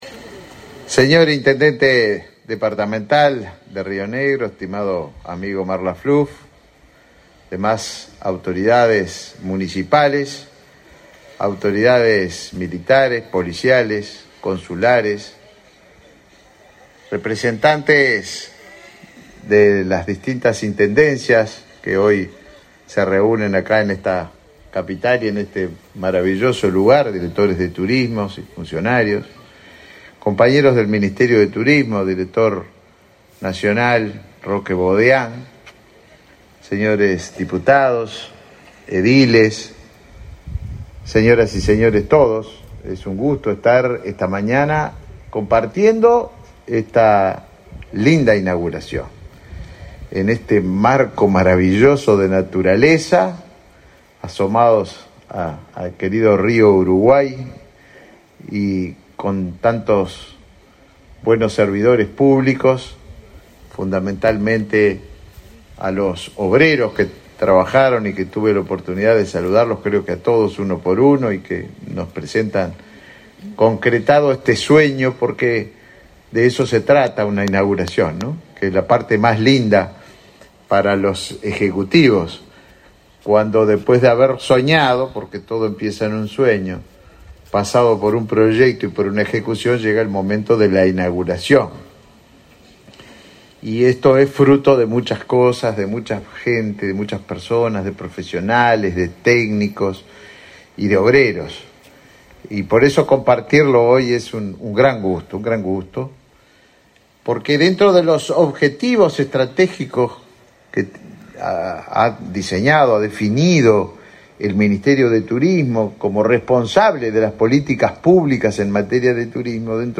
Palabras del ministro de Turismo, Tabaré Viera
El Ministerio de Turismo inauguró obras de infraestructura turística en balneario Las Cañas, este 19 de mayo.